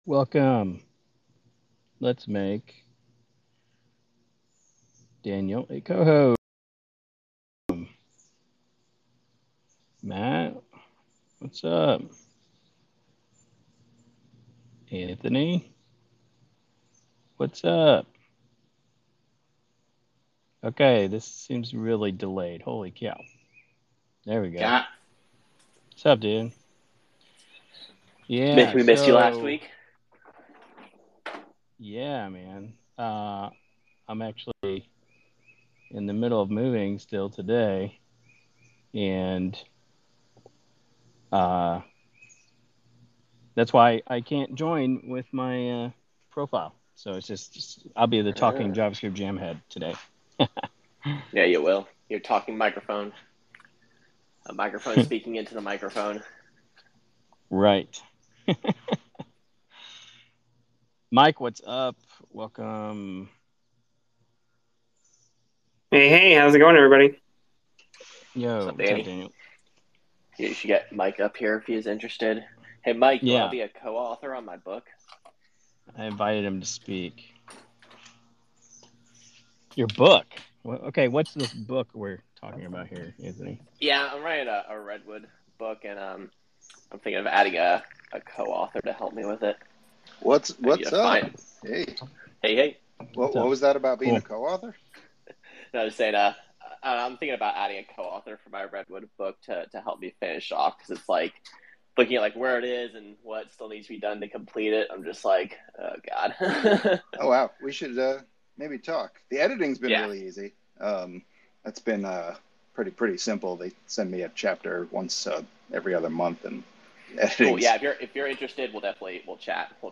A panel debates Douglas Crockford’s call to retire JS, alternatives like Blazor, and evolving definitions of Jamstack and composability